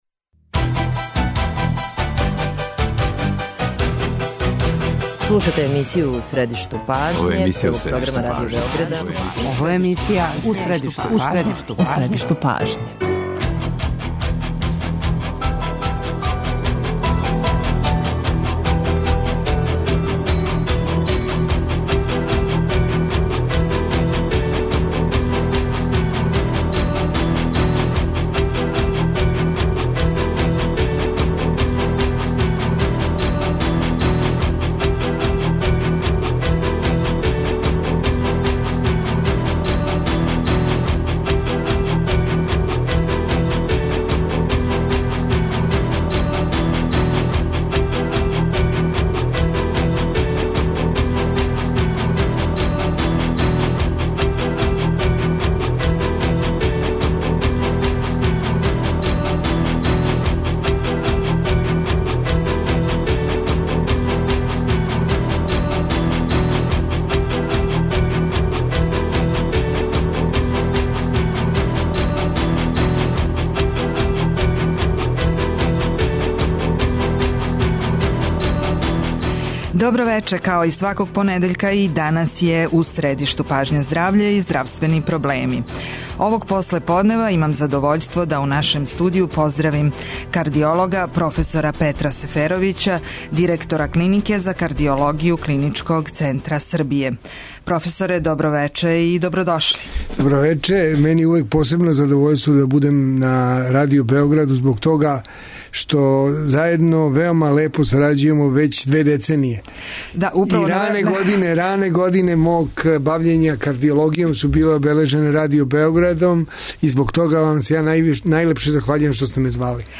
Разговараћемо о европским и светским трендовима у лечењу срчане слабости, и о могућностима Србије да испрати новине у овој области. Наш гост одговараће и на питања слушалаца.